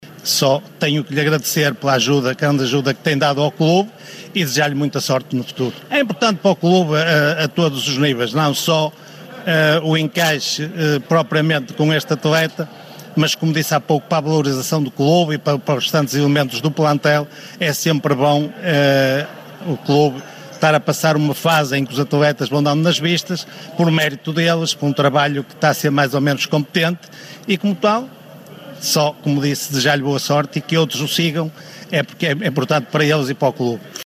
falou hoje aos jornalistas, à margem de uma reunião de presidentes dos clubes da 1.ª Liga